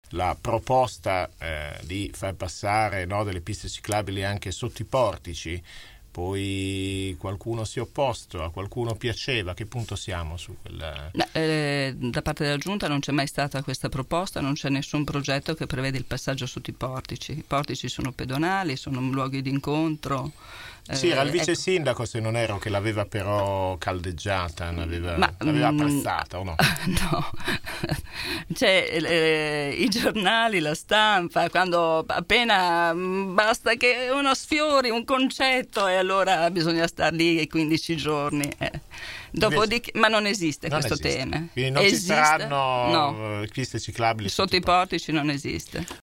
“C’è l’obiettivo di aumentarle perché i mezzi pubblici possano viaggiare più velocemente” ha fatto sapere questa mattina durante il microfono aperto nei nostri studi l’assessore al Traffico e alla Mobilità, Simonetta Saliera.